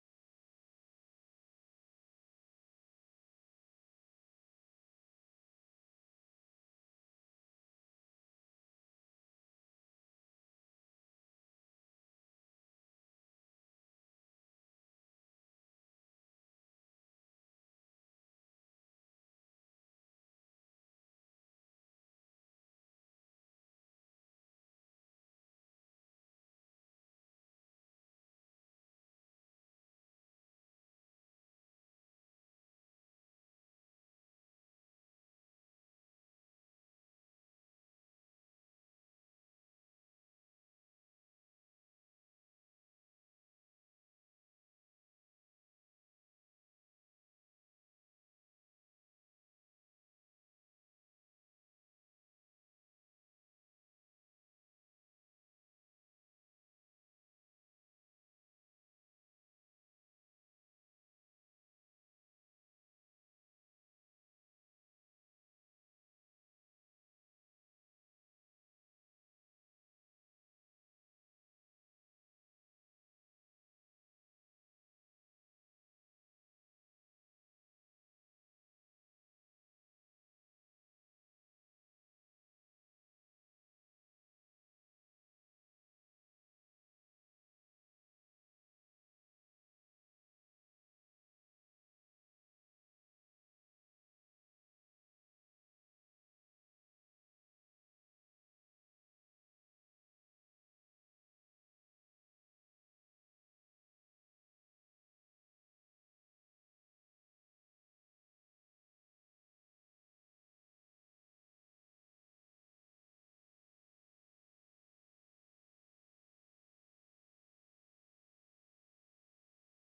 Praise and worship from September 22nd 2024
Praise Worship